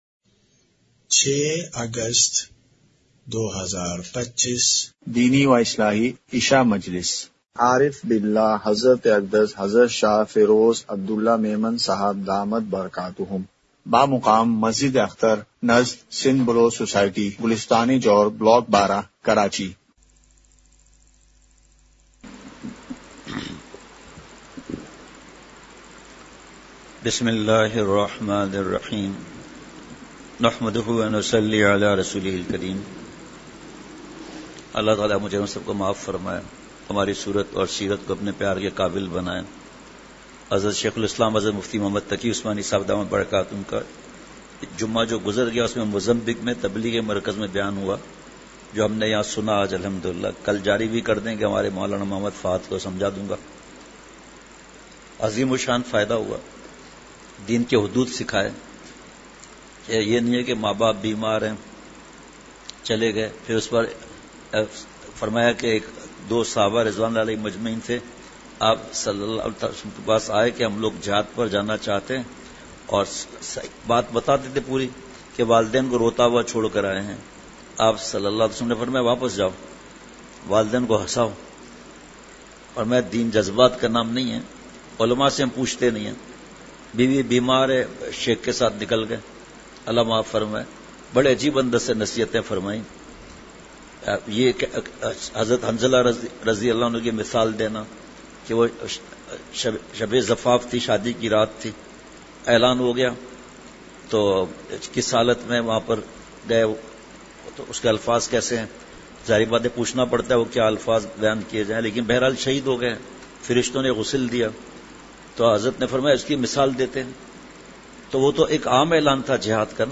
اصلاحی مجلس
مقام:مسجد اختر نزد سندھ بلوچ سوسائٹی گلستانِ جوہر کراچی